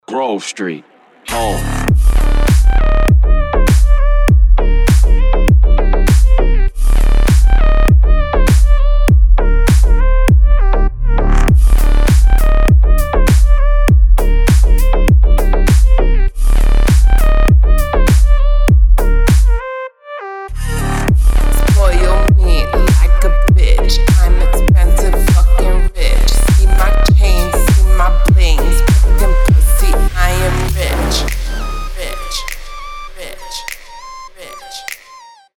• Качество: 320, Stereo
G-House